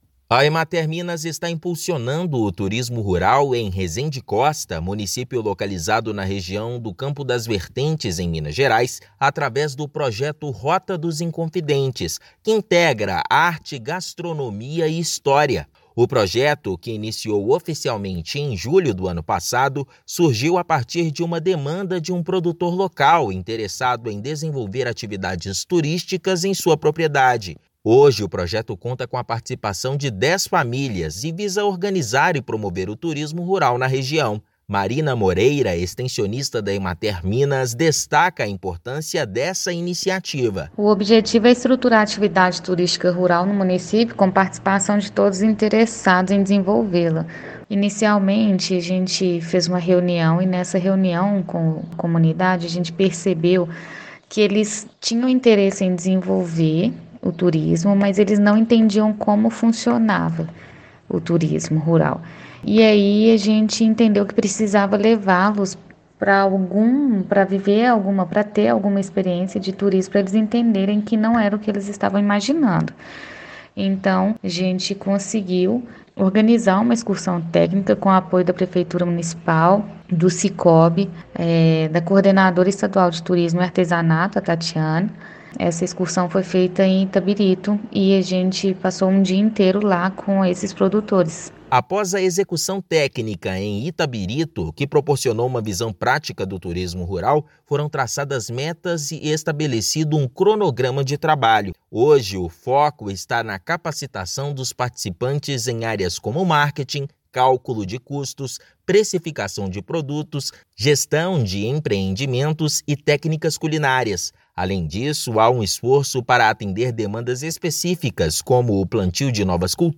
Projeto reúne dez famílias e integra arte, gastronomia e história, tornando a região atraente e vibrante. Ouça matéria de rádio.